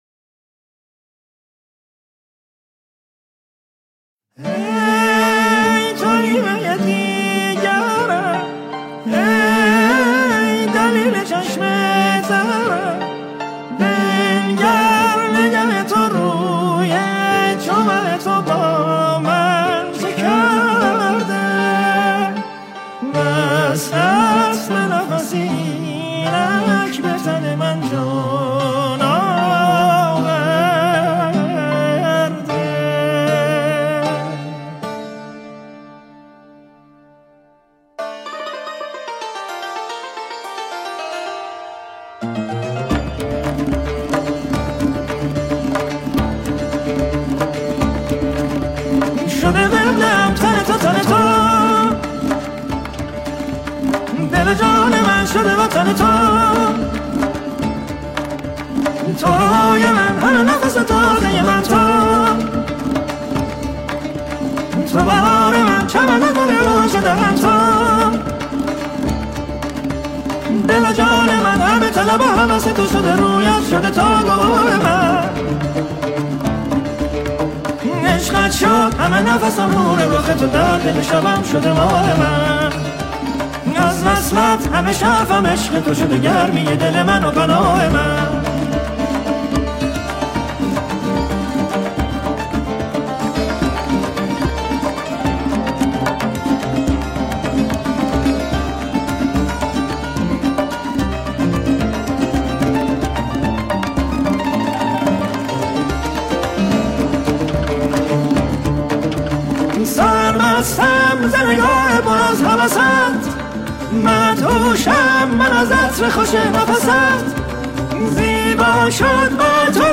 کیفیت ضبط صدا پایین است.
ماشاالله خیلی بالا خونده